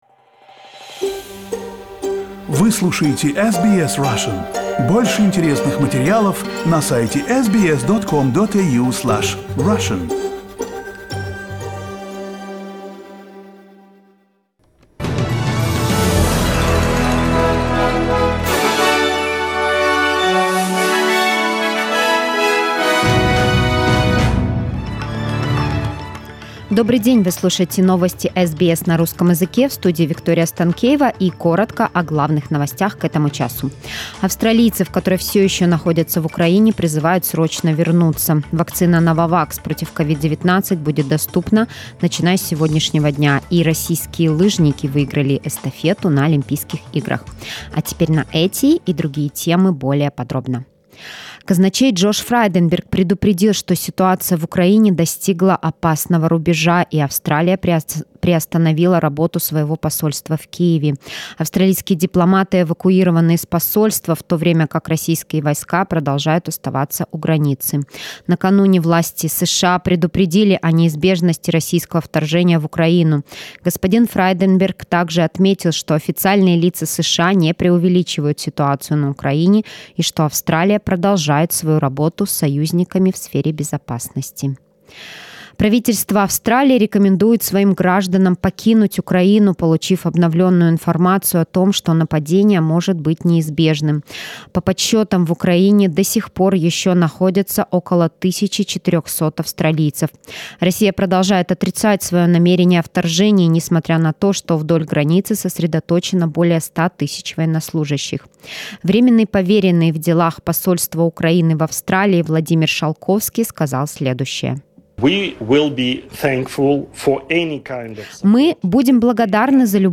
SBS news in Russian - 14.02